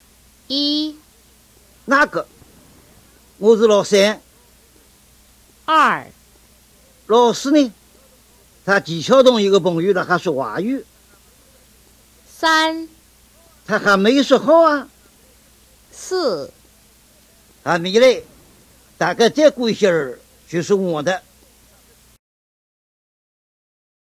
In each of the audio files below, the speaker will say the following, at least how they would say the same thing in their dialect.
3. Hángzhōu Dialect (Wu Group; Zhejiang Province)
03-hangzhou-hua.m4a